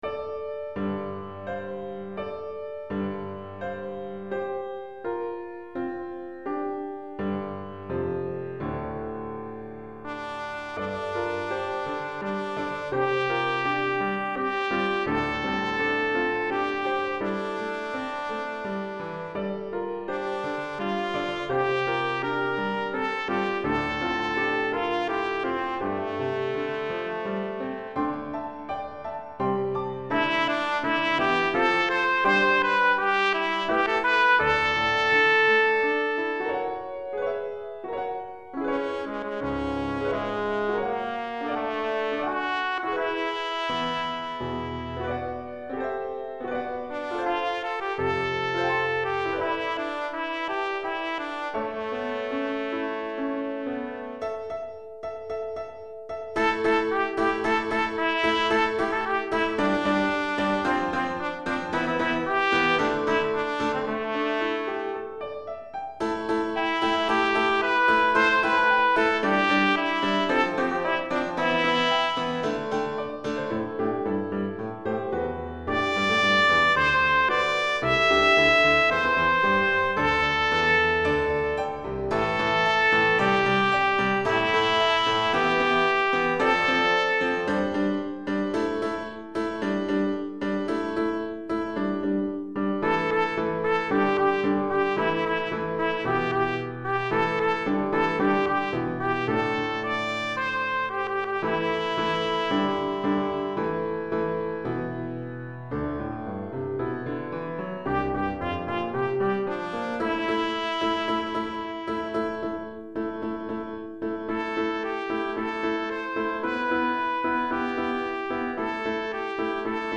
Trompette en Sib et Piano